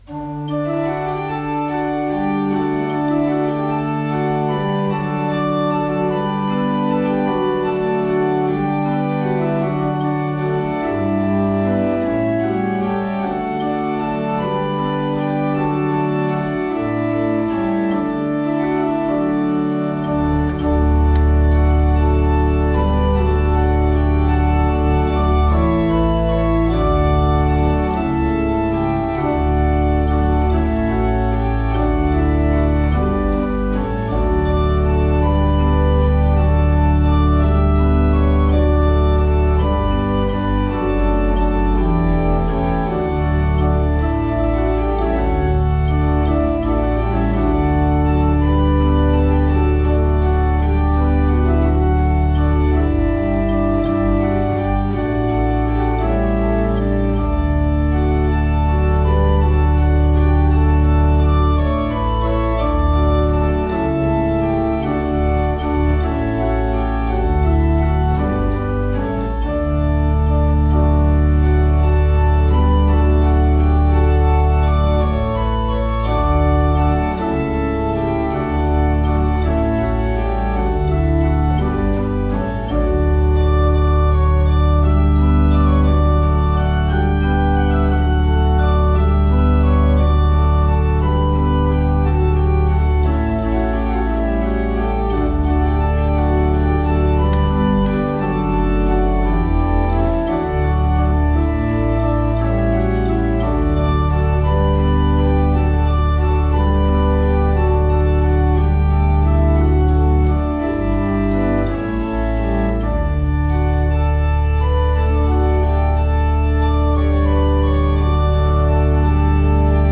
Orgel: Vorspiel
vorspiel_misericordias_domini.mp3